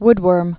(wdwûrm)